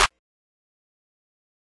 Sizzle Clp&Snr.wav